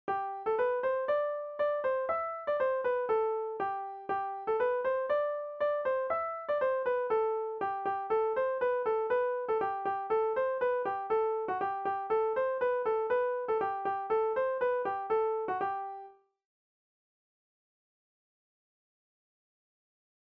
Enumératives - Nombres en décroissant
danse : branle : courante, maraîchine